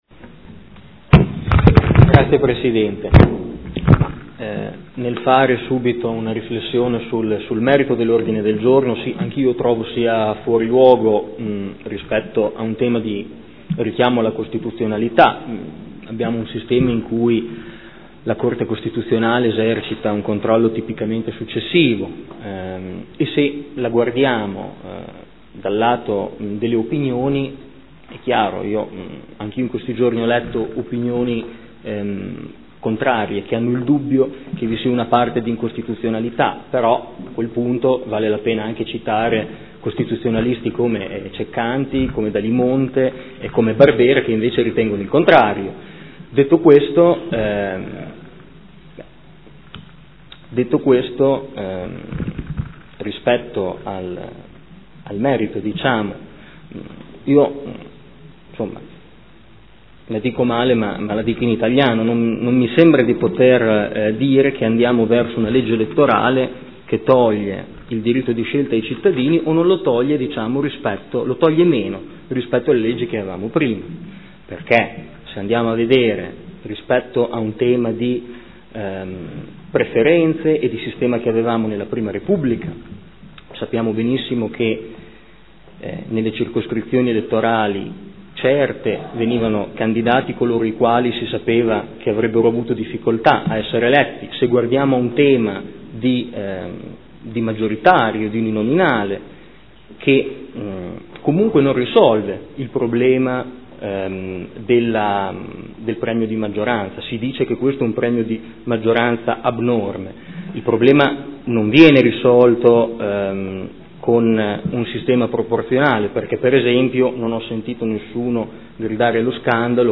Seduta del 30/04/2015 Odg 58621 su Italicum